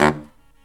LOHITSAX14-L.wav